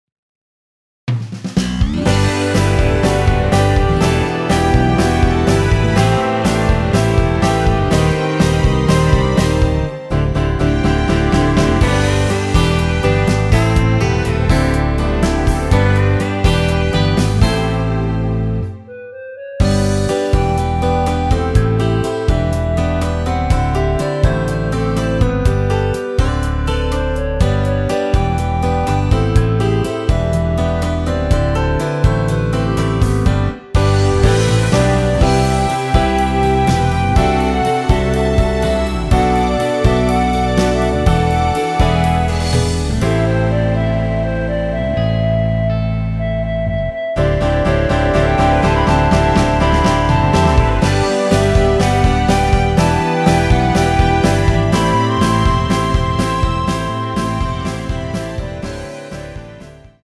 원키에서(+4)올린 멜로디 포함된 MR 입니다.
앞부분30초, 뒷부분30초씩 편집해서 올려 드리고 있습니다.
중간에 음이 끈어지고 다시 나오는 이유는